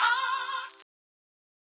Ahh.wav